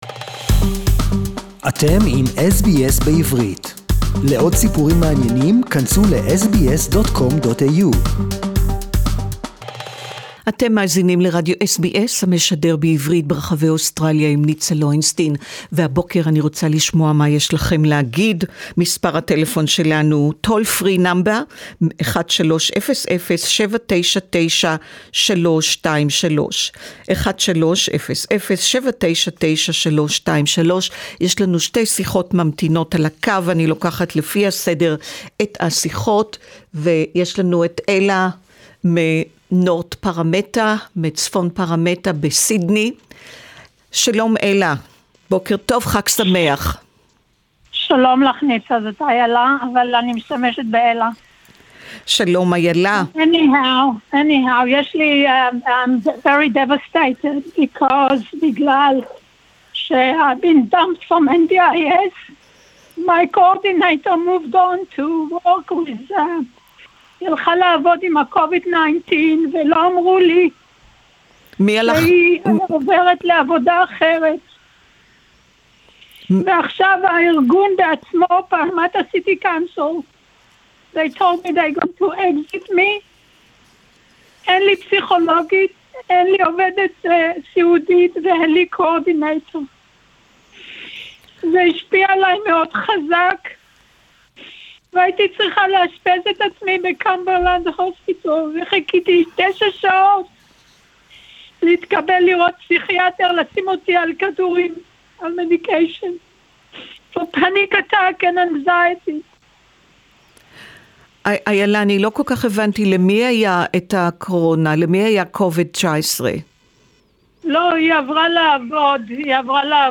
Hebrew Talk-back)12.4.2020